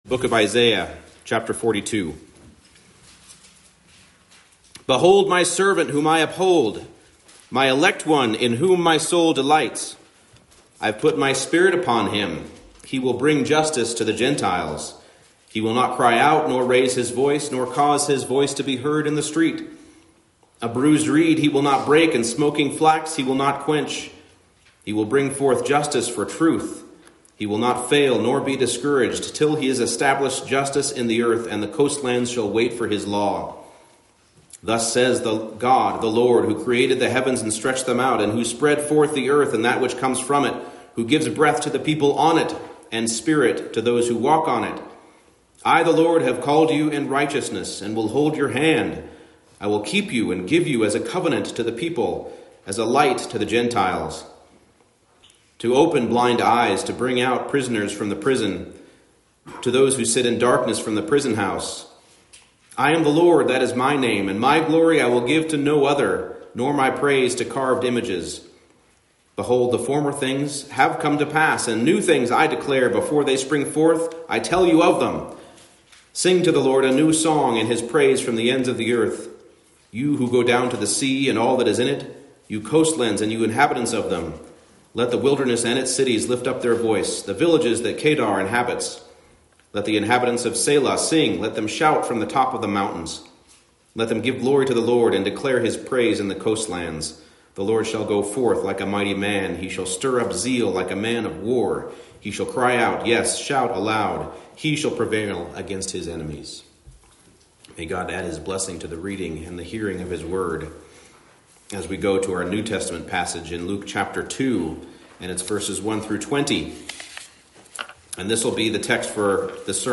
Service Type: Special Worship Service